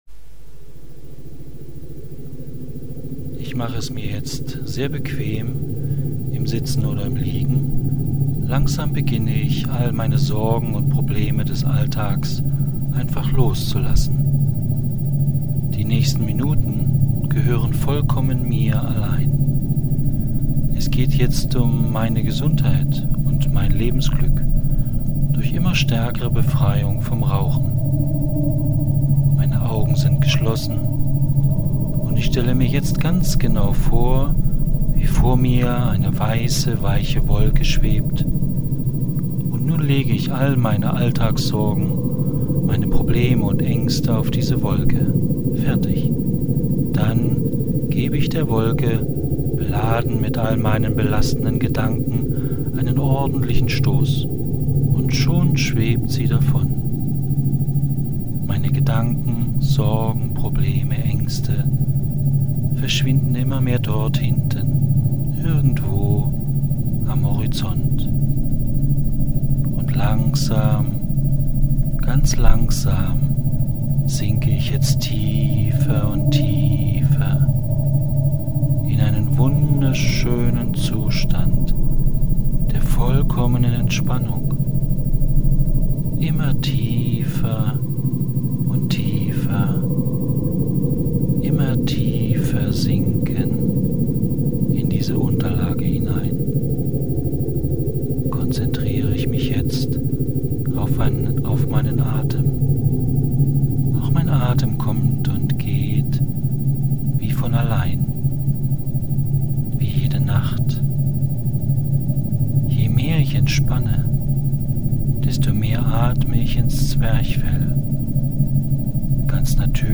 Hypnose Rauchstopp und Audio-Neuroprogrammierung Suchtreduktion